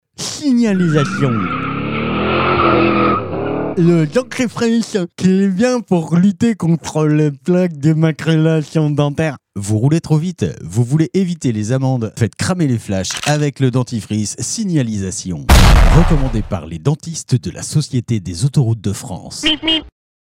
Fausses Pubs RADAR